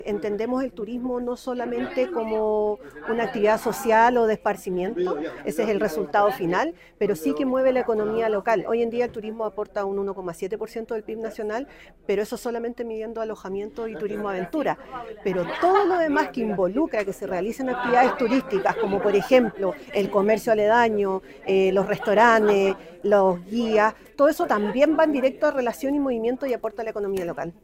La ceremonia contó además con la participación de la directora regional de Sernatur, Carolina Carrasco, quien resaltó la relevancia económica de esta alianza: